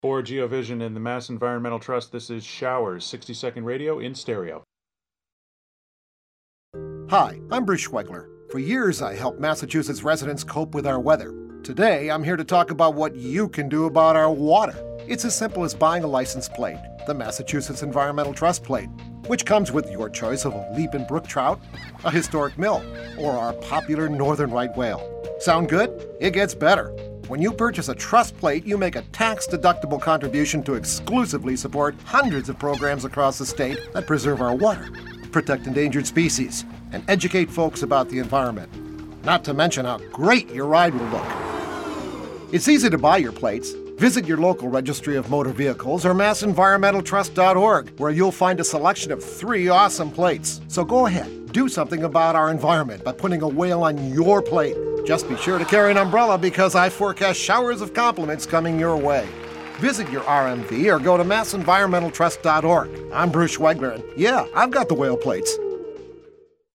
“Showers of Compliments” radio spot